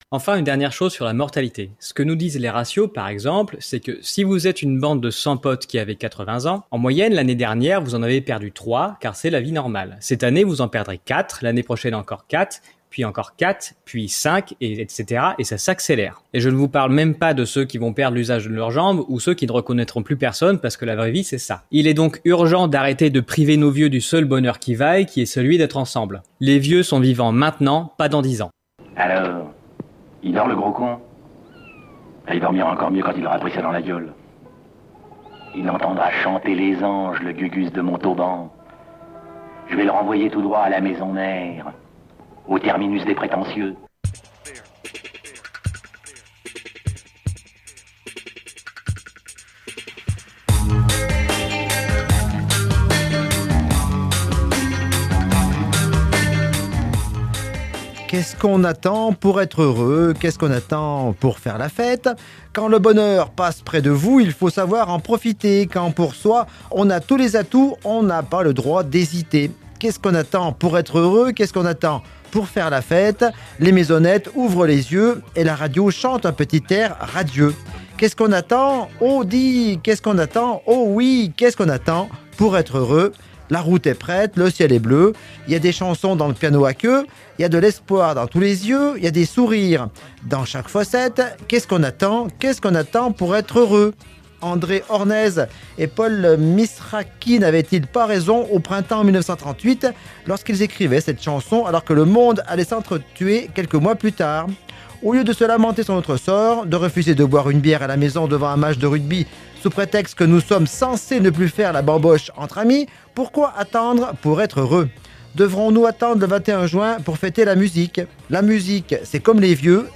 Radio B, la radio locale de Bourg-en-Bresse et des Pays de l'Ain